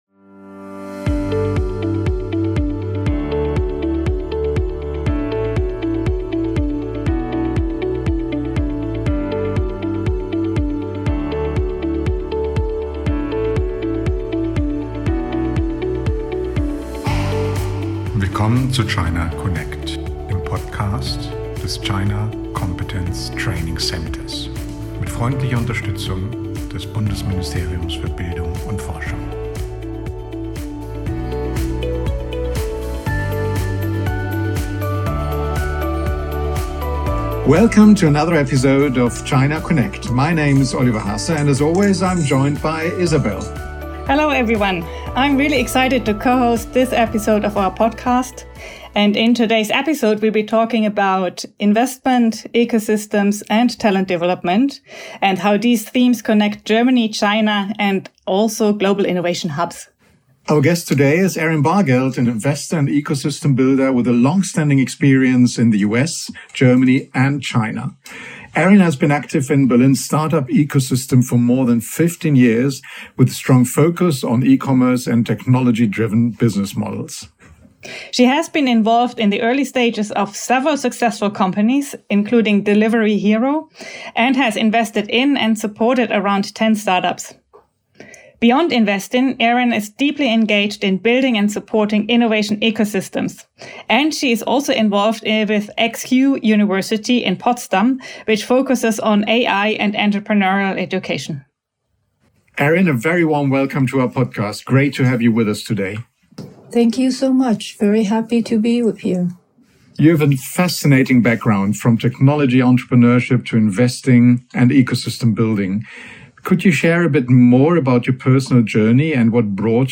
We discuss what makes Berlin attractive for international founders and investors, how the city compares to China's speed and Silicon Valley's scale, and why strong networks, education, and the right regulatory environment are essential for a thriving ecosystem. The conversation also looks ahead to the future of work, highlighting why teams matter more than ideas, how AI is reshaping careers, and what skills the next generation of entrepreneurs will need to succeed.